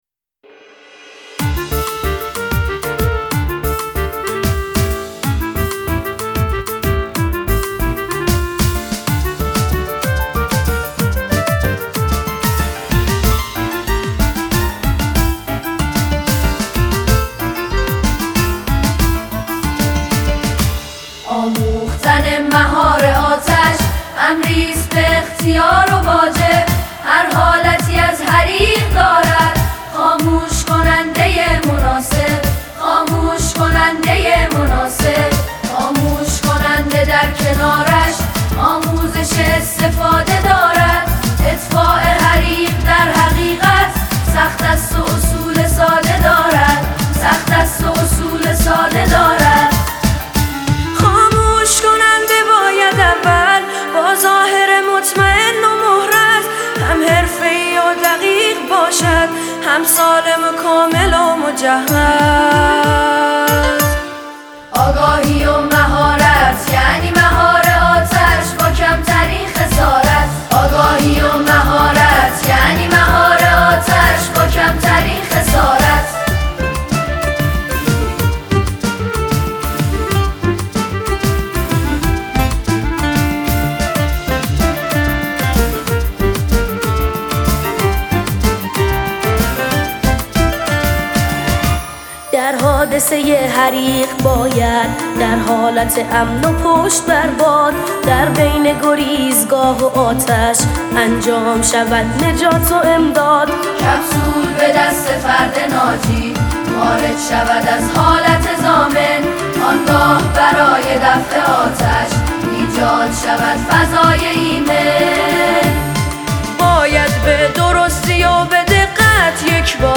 ترانه زیبای کودکانه « خاموش کننده » به کودکان عزیز و خوب تقدیم می شود.